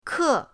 ke4.mp3